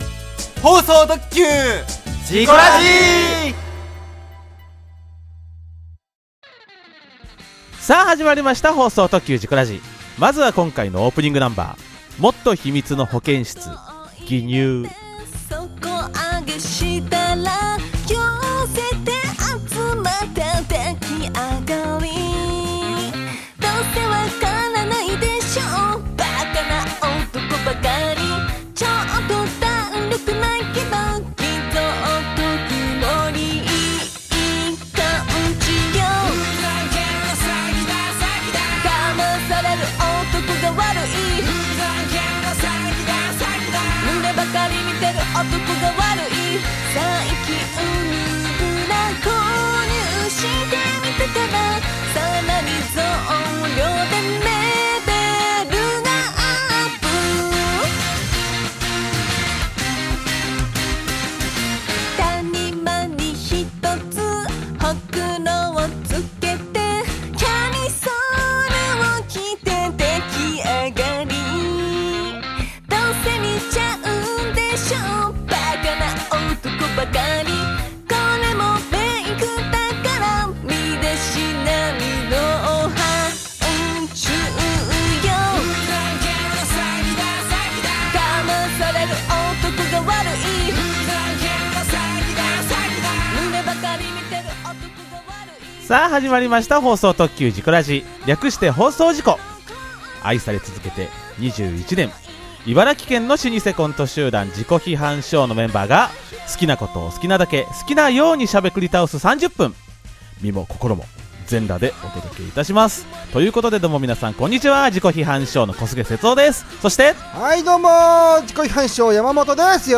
茨城県のコント集団『自己批判ショー』のメンバーが、好きなことを好きなだけ、好きな様にしゃべくり倒す３０分！